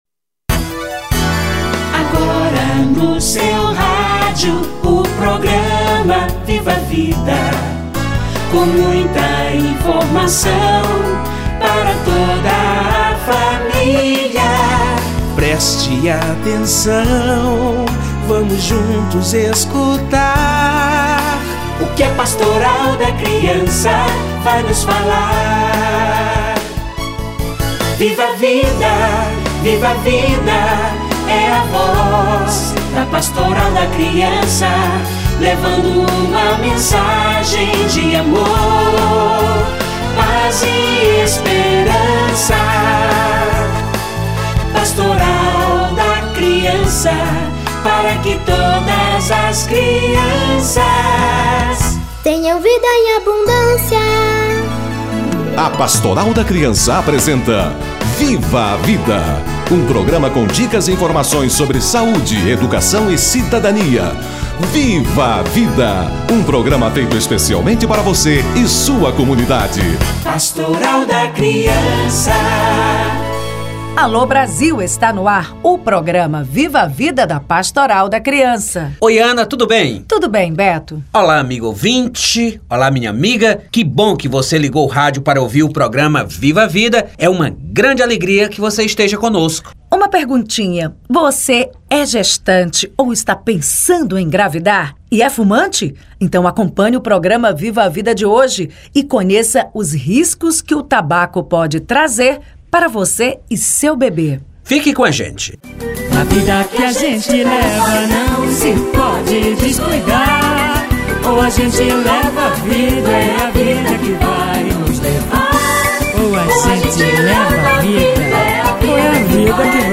Os males do tabaco - Entrevista